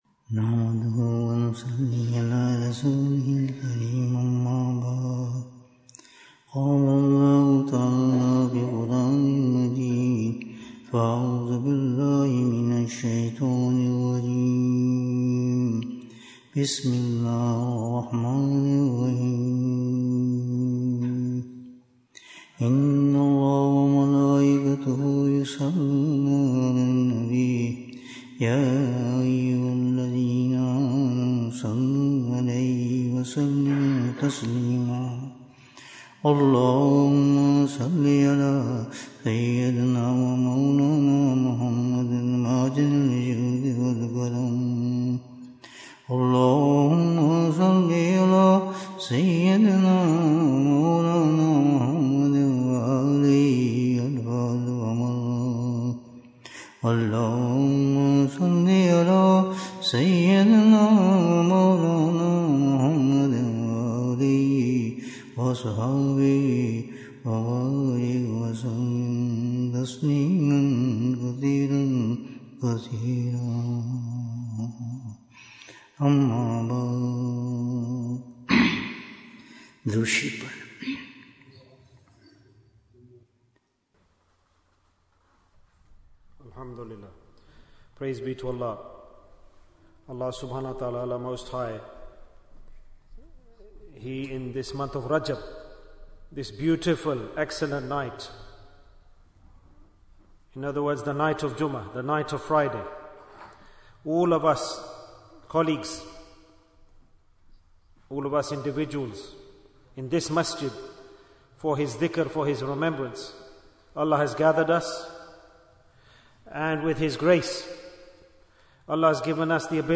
Stages of Nearness to Allah Ta'ala Bayan, 56 minutes9th January, 2025